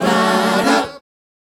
Ba Dah 152-F#.wav